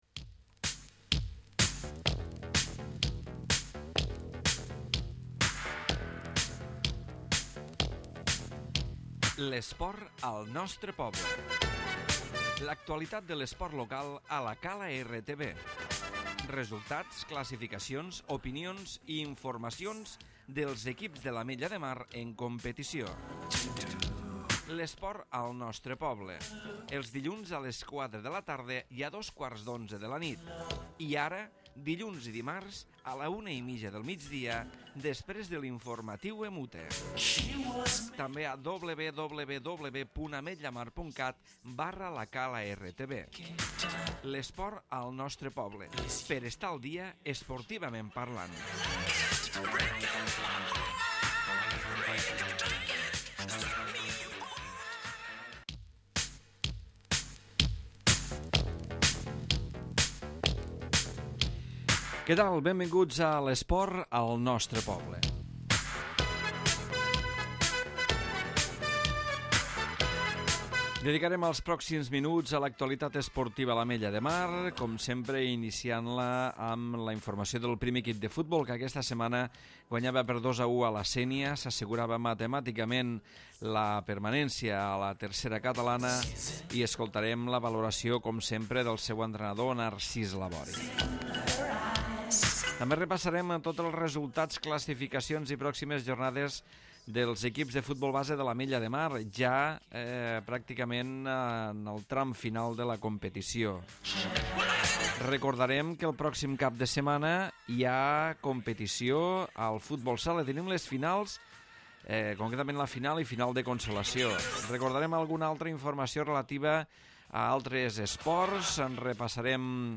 Programa esportiu de l'actualitat dels equips i esportistes de l'Ametlla de Mar.